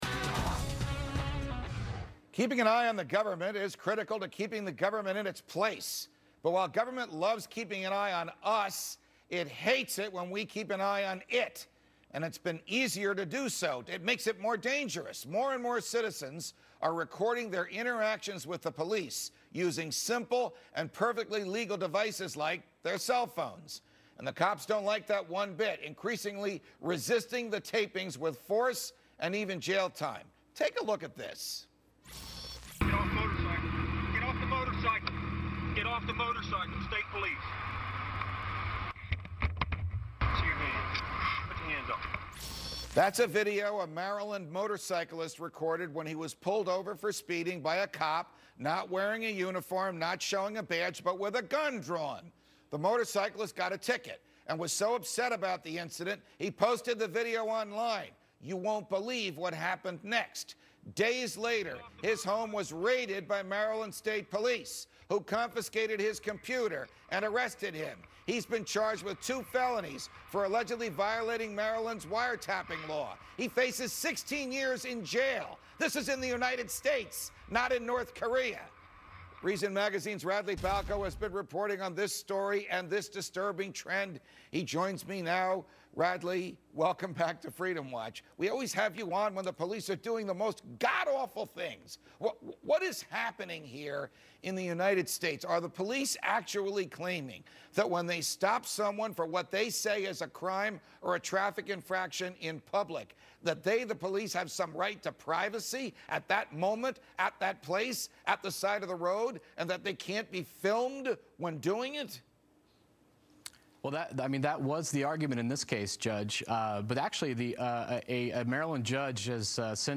Reason Senior Editor Radley Balko appeared on Fox Business' Freedom Watch With Judge Napolitano to discuss the right of citizens to record police officers and the negative consequences that may result from potential state bans of that practice.